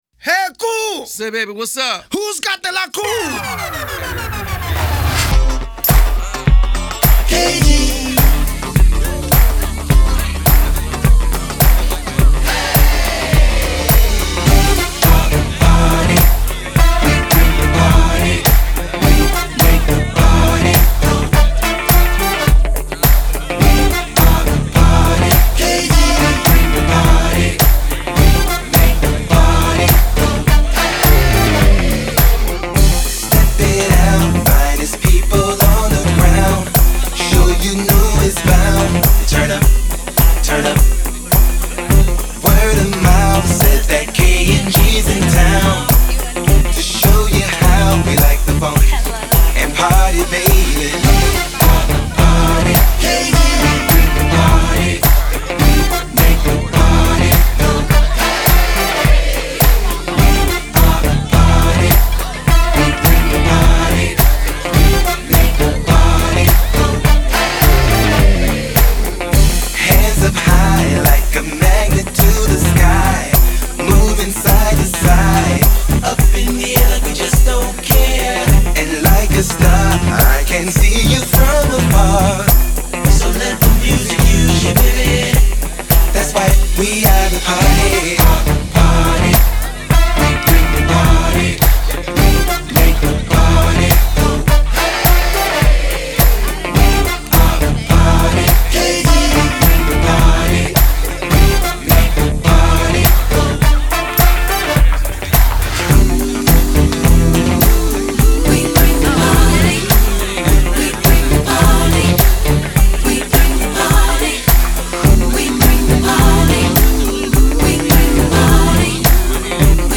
Genre : Funk